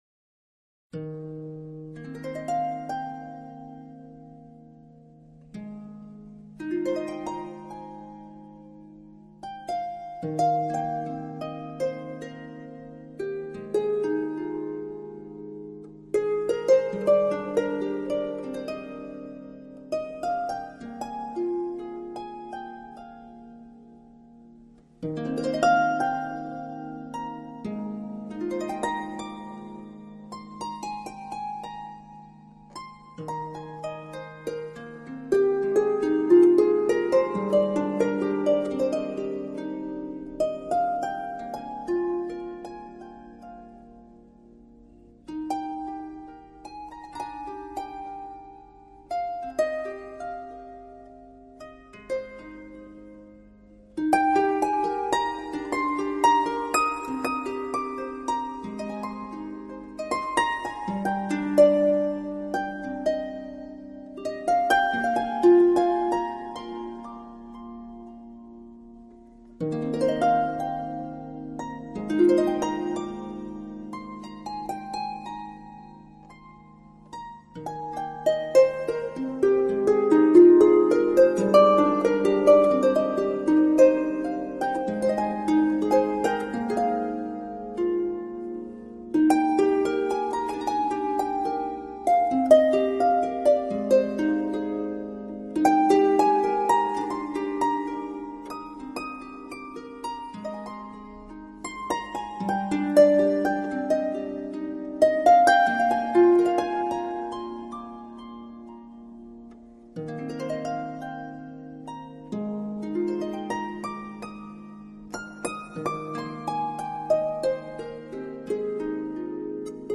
大提琴、竖琴与 长笛完美交织在一起
完美的录音效果，呈现出 顶极的逼真质感。